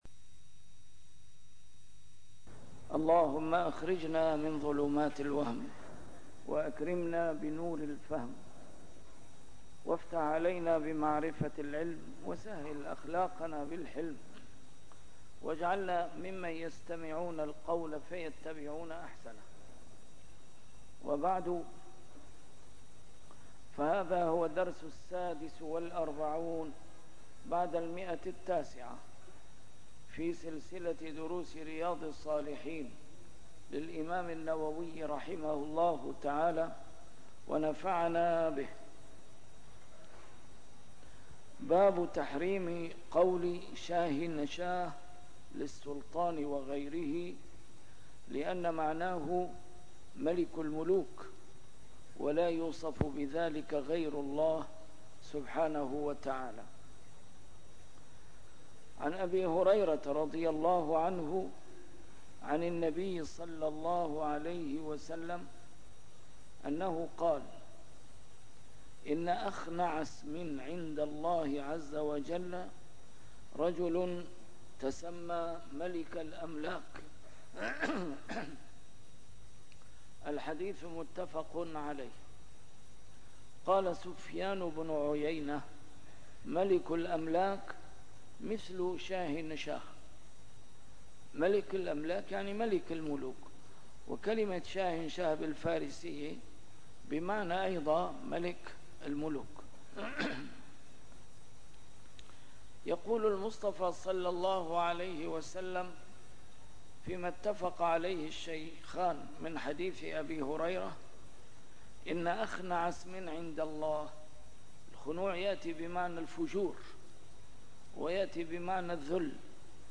A MARTYR SCHOLAR: IMAM MUHAMMAD SAEED RAMADAN AL-BOUTI - الدروس العلمية - شرح كتاب رياض الصالحين - 946- شرح رياض الصالحين: تحريم قول شاهنشاه للسلطان - النهي عن مخاطبة الفاسق بسيِّد